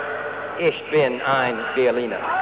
Schöneberg town hall, formerly seat of the West Berlin government, saw a  historical moment on 26 June, 1963 when John F. Kennedy, on his visit to the  Cold-War border town, spoke the unforgotten words: "Ich bin ein Berliner"…